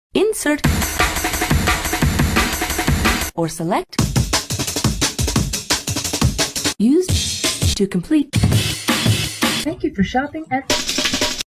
Insert *beatbox* Or Select *beatbox* Sound Effects Free Download